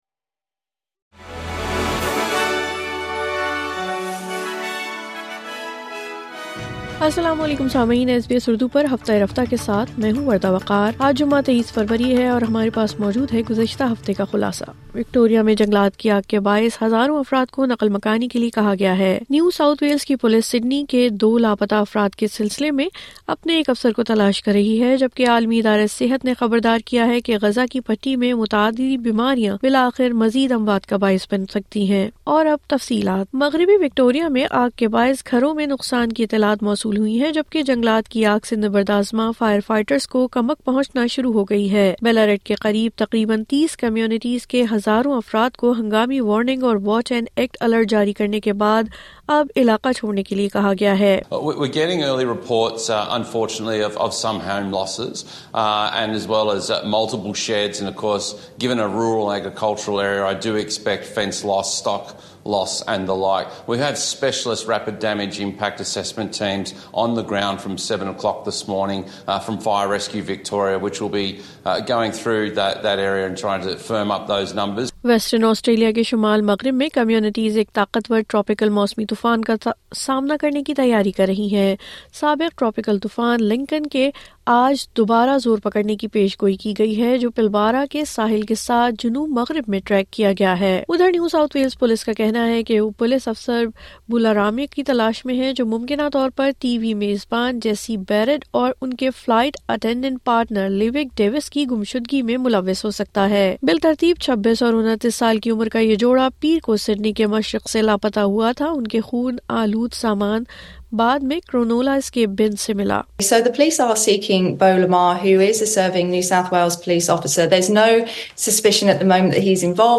نیو ساؤتھ ویلز کی پولیس سڈنی کے دو لاپتہ افراد کے سلسلے میں اپنے ایک افسر کو تلاش کر رہی ہے۔ عالمی ادارہ صحت نے خبردار کیا ہے کہ غزہ کی پٹی میں متعدی بیماریاں مزید اموات کا سبب بن سکتی ہیں۔ مزید تفصیل کے لئے سنئے اردو خبریں